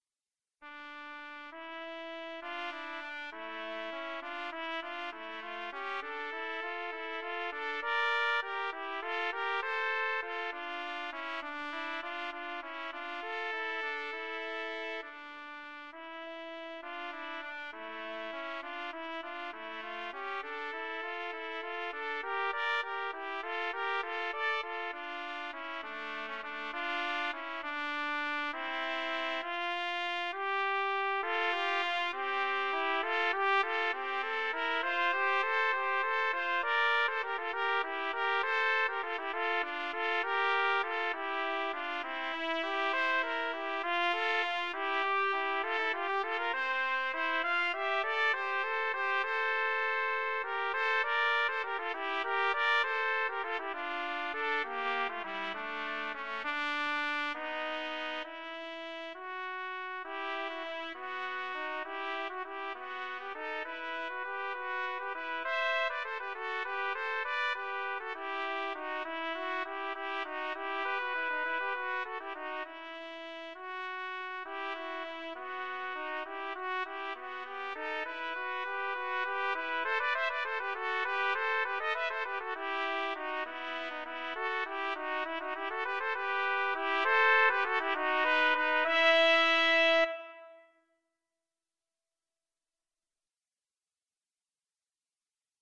A trumpet duet in a baroque style, in triple meter.
Baroque Period